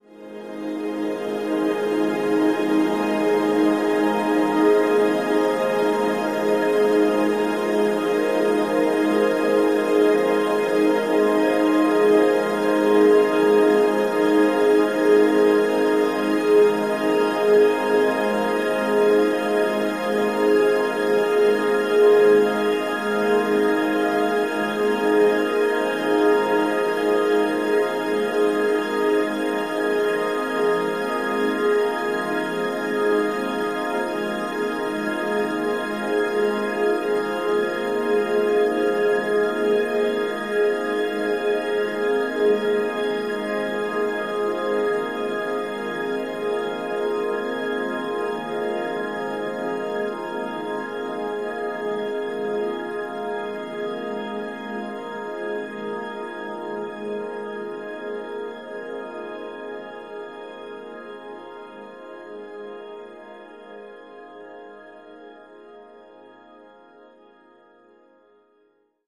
Ambiance atmosphere happy joyful